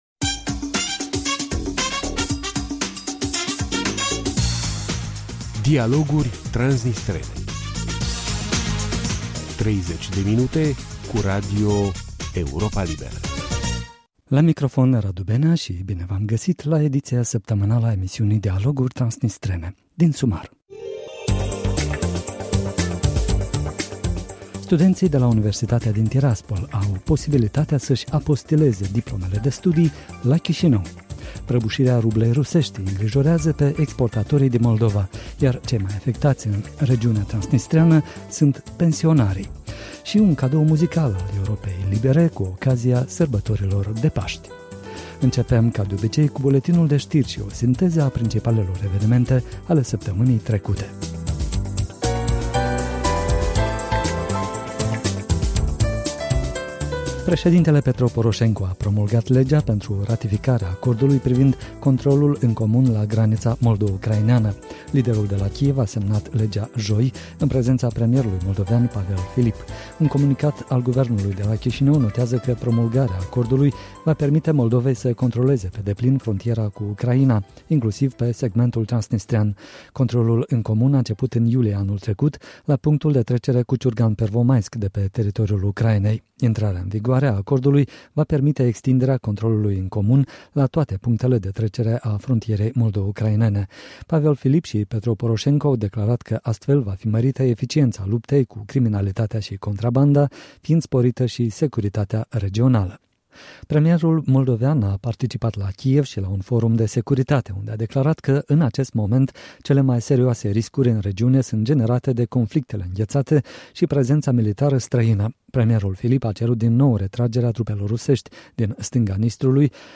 și bine v-am găsit la ediția săptămânală a emisiunii Dialoguri transnistrene. Din sumar: Studenții de la universitatea din Tiraspol au posibilitatea să-și apostileze diplomele de studii la Chișinău.